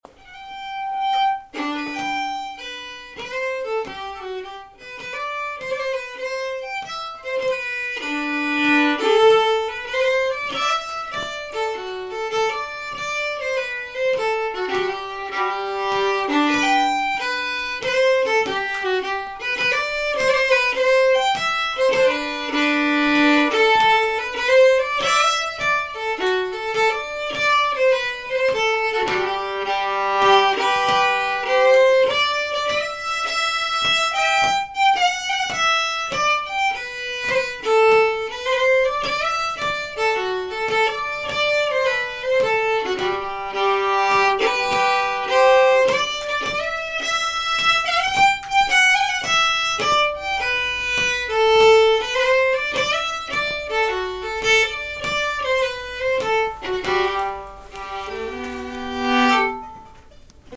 Hambo – 2